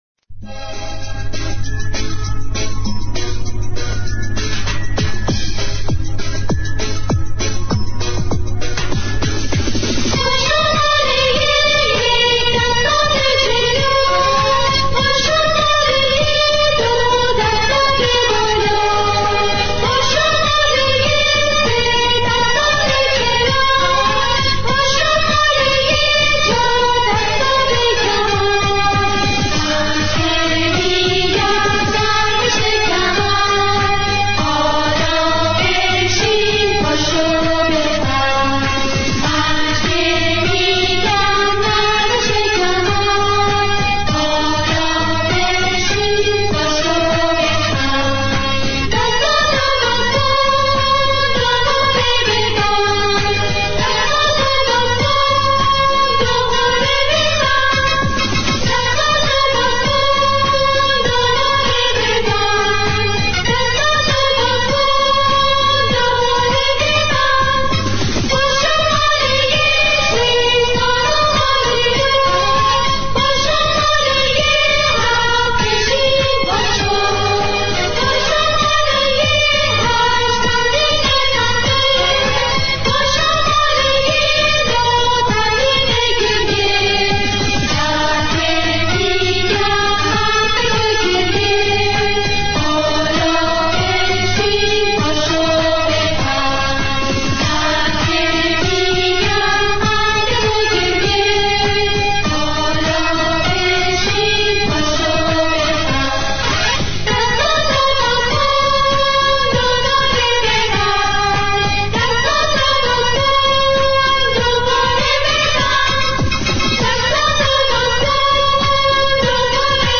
دسته : نیوایج